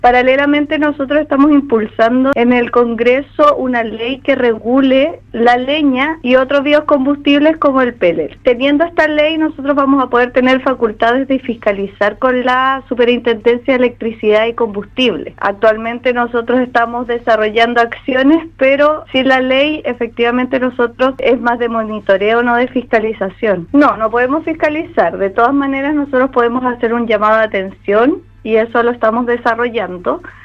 En conversación con Radio Sago, la Seremi de Energía de la región de Los Lagos, Liliana Alarcón, se refirió a la escasez de pellet por la falta de materia prima, lo cual fue anunciado por la Asociación Chilena de Biomasa (ACHBIOM) y que se tradujo en una disponibilidad limitada del producto en la zona centro sur.